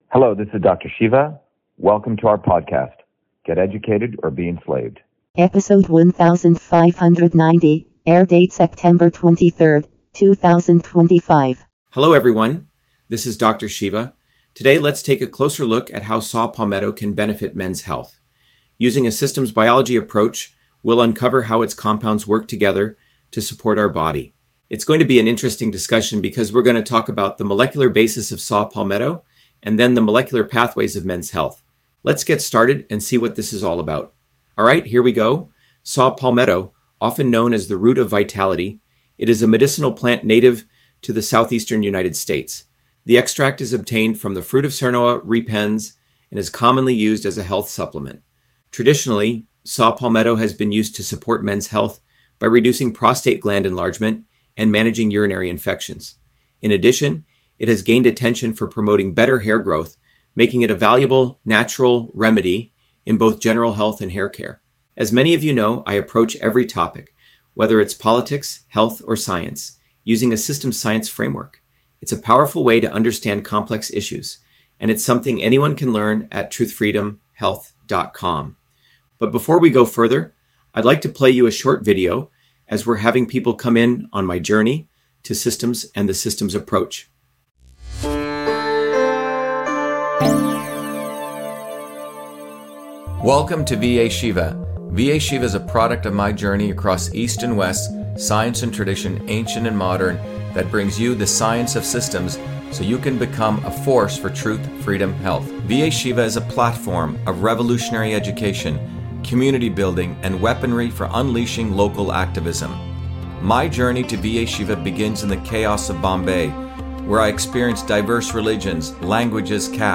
In this interview, Dr.SHIVA Ayyadurai, MIT PhD, Inventor of Email, Scientist, Engineer and Candidate for President, Talks about Saw Palmetto on Men’s Health: A Whole Systems Approach